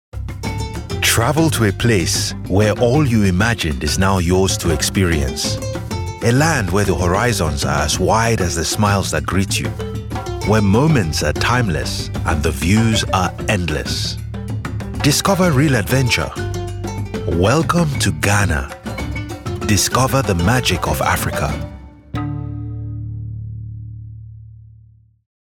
20s-40s. Male. African/Black British.
Commercials